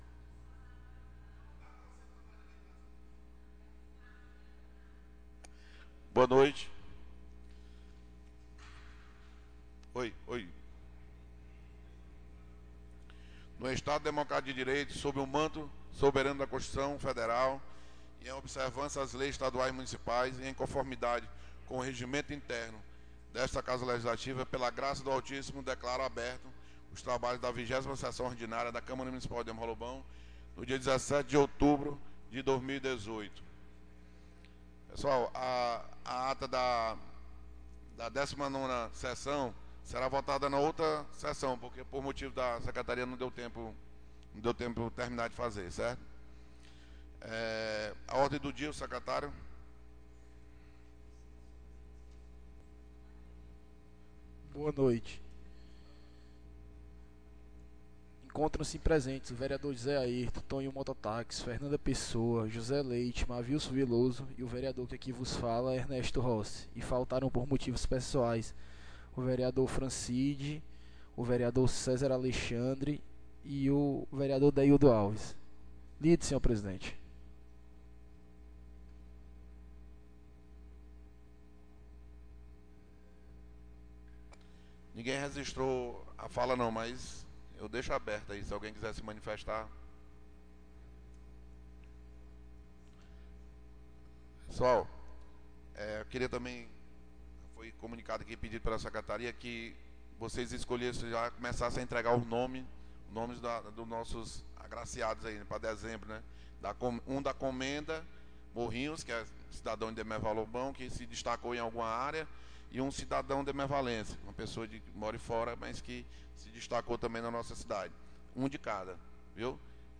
20ª Sessão Ordinária 17/10/2018